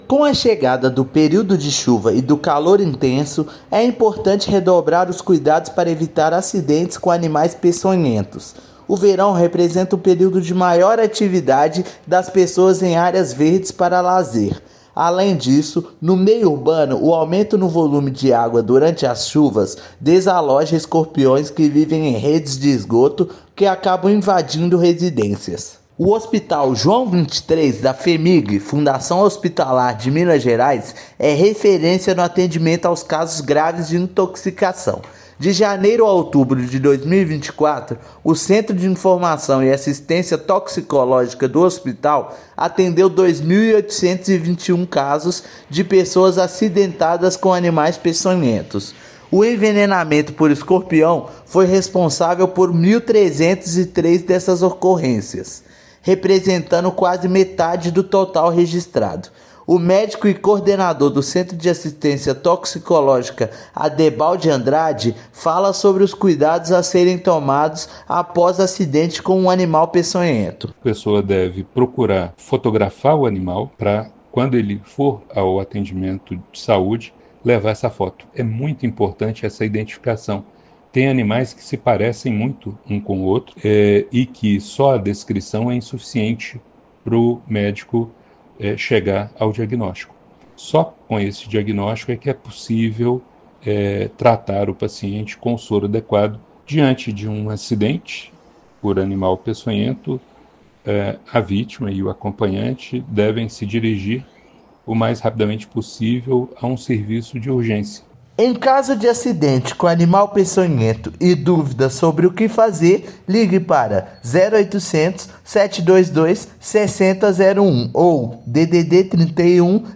[RÁDIO] Calor e período chuvoso aumentam ocorrências com animais peçonhentos
Especialista da Toxicologia do Hospital João XXIII faz alerta e ensina como proceder em caso de acidentes. Ouça matéria de rádio.
Rádio_matéria_Animais_peçonhentos.mp3